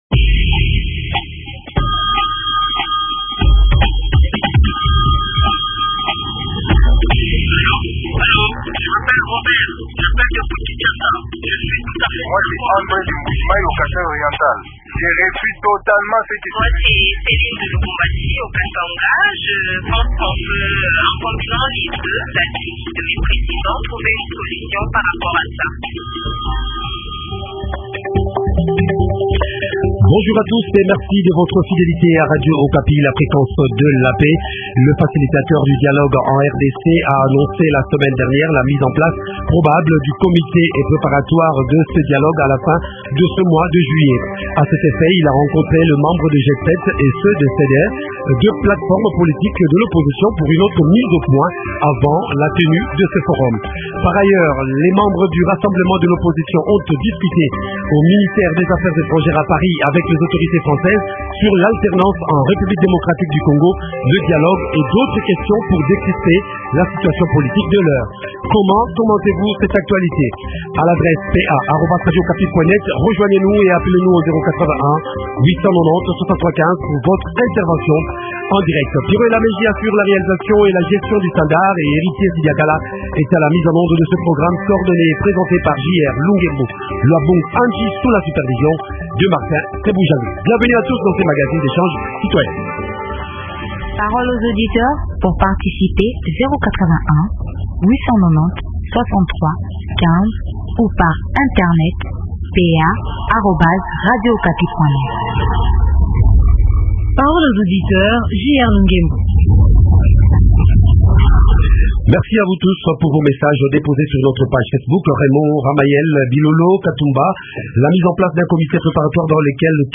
Invité: Jean Lucien Busa, député et coordonnateur-porte-parole du courant des démocrates rénovateurs.